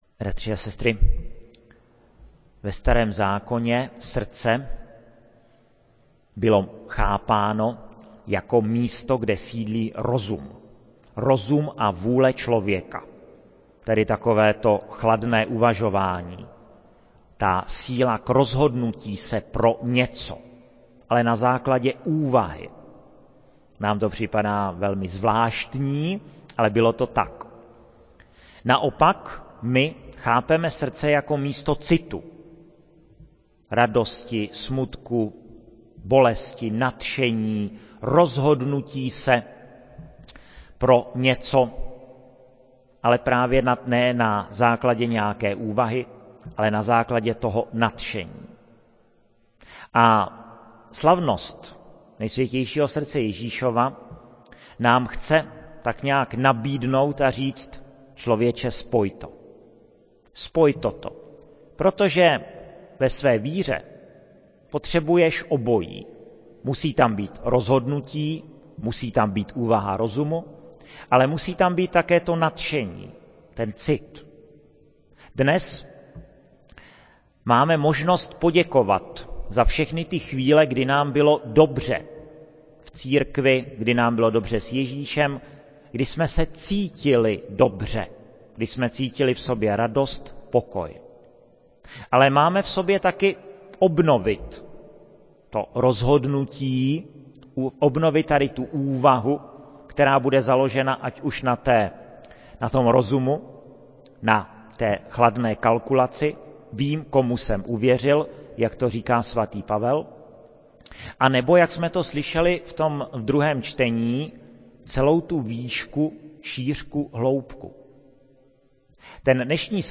15.06.2012 - pátek , kostel sv. Jakuba ve Veverské Bítýšce
Slavnost Nejsvětějšího srdce Ježíšova
homilie0599.mp3